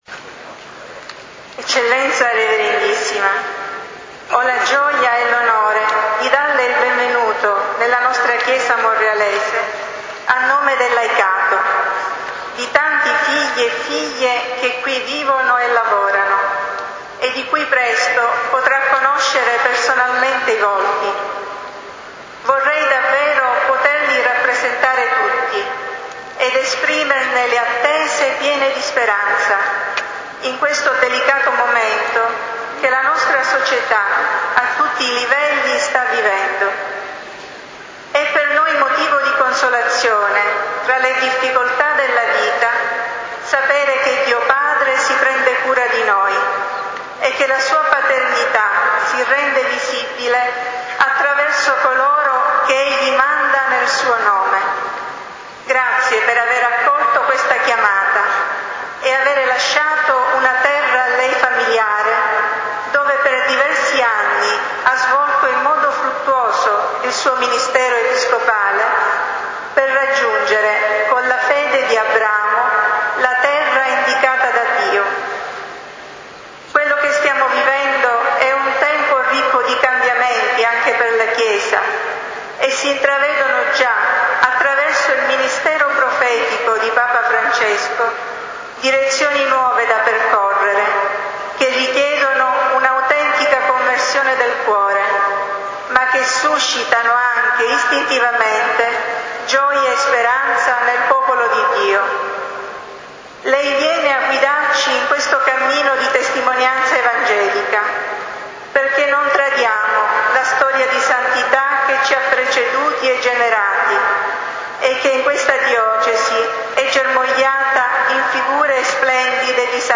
Ingresso Arcivescovo Pennisi